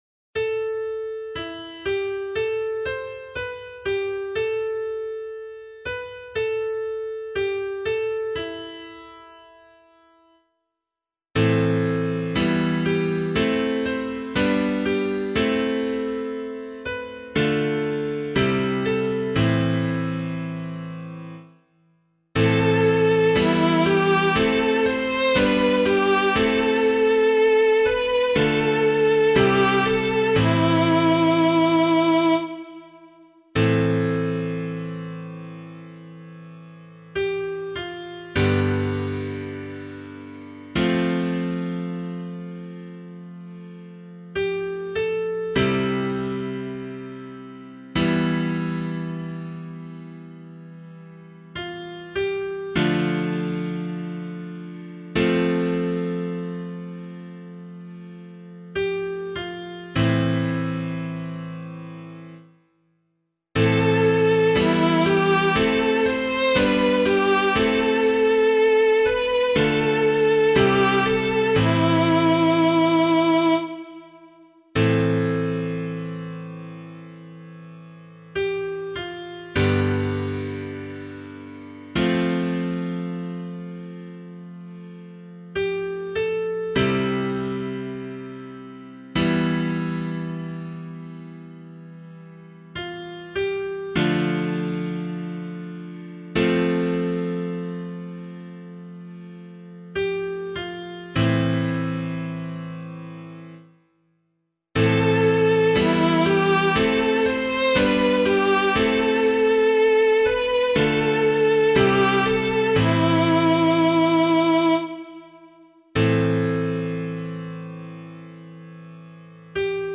pianovocal